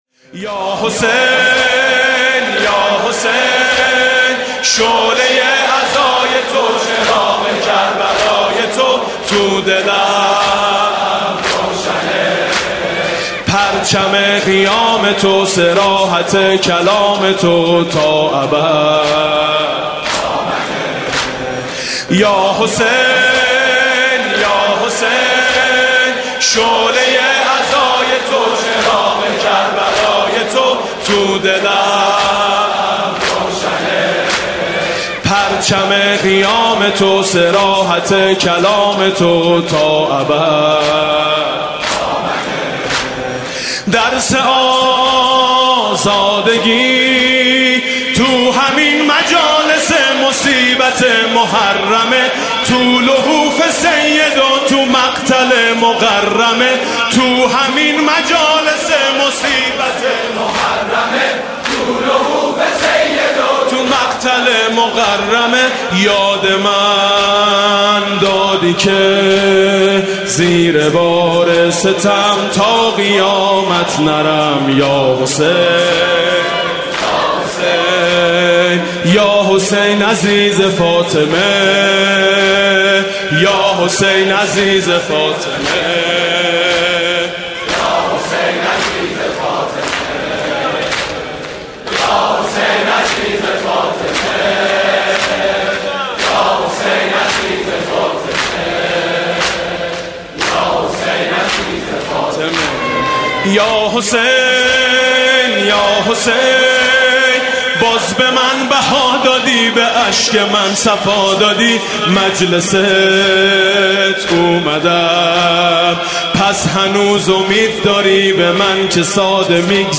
حاج میثم مطیعی
شب سوم محرم 96 - هیئت میثاق - دم - یا حسین یا حسین، من کجا لیاقت اقامه عزای تو
دم حاج میثم مطیعی محرم 96 هیئت میثاق با شهدا 96